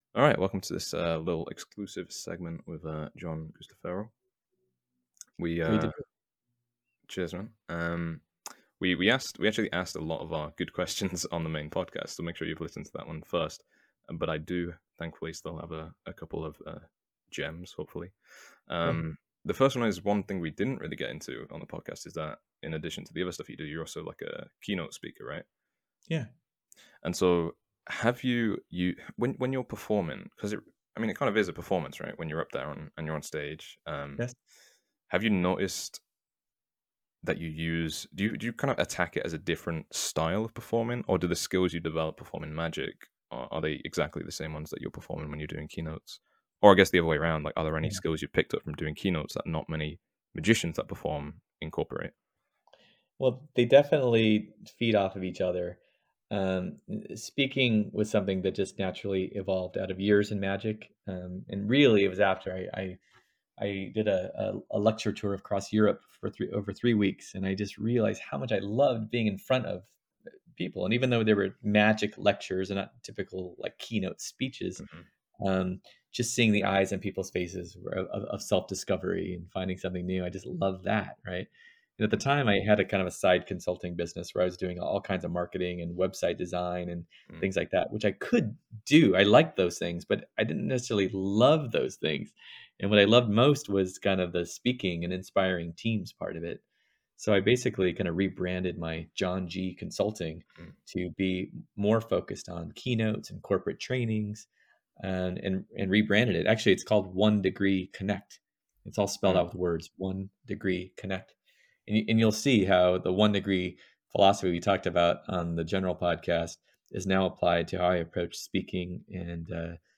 Audio Training